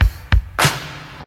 96 Bpm 80s Pop Drum Loop A Key.wav
Free drum groove - kick tuned to the A note. Loudest frequency: 1947Hz
96-bpm-80s-pop-drum-loop-a-key-see.ogg